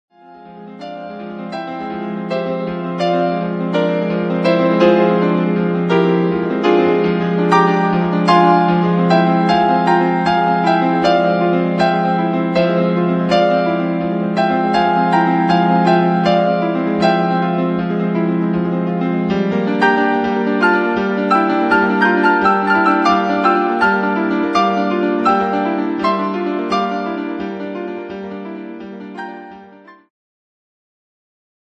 ジャンル Progressive
インストゥルメンタル
ピアノフューチュア
癒し系